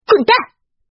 分段配音
滚蛋！.mp3